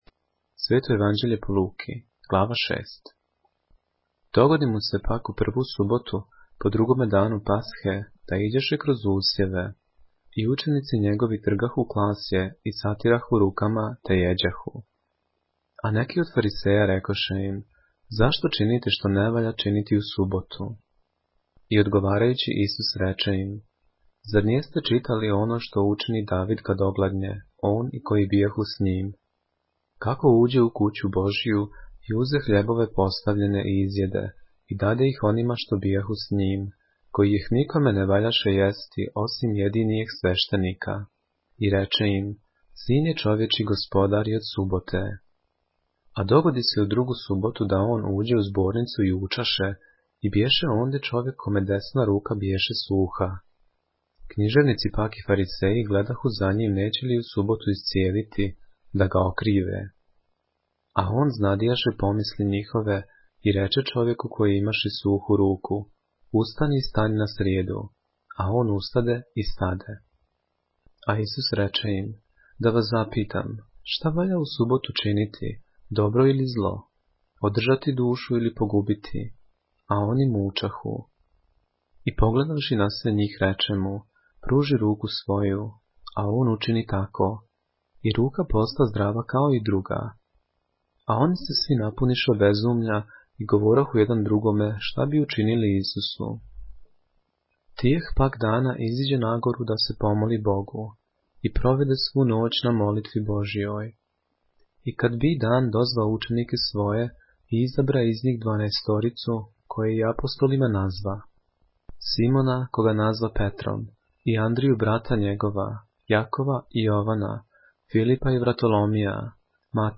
поглавље српске Библије - са аудио нарације - Luke, chapter 6 of the Holy Bible in the Serbian language